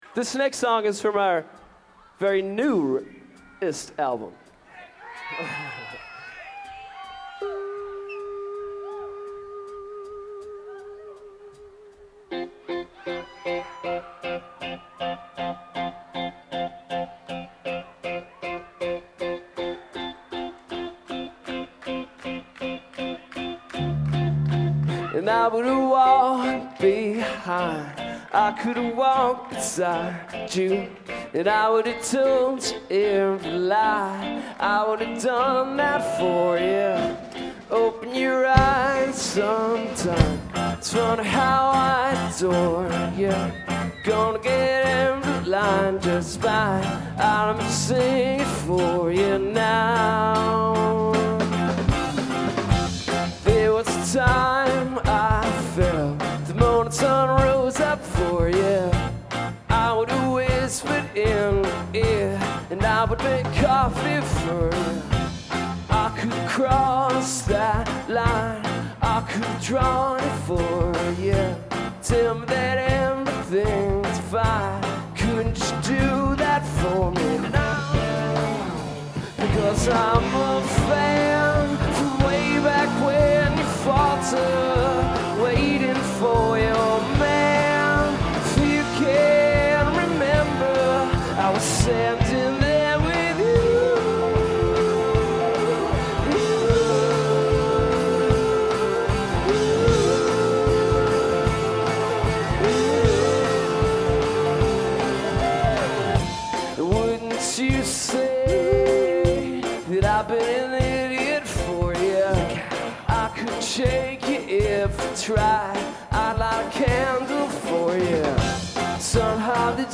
quinnipiac college april 25 2003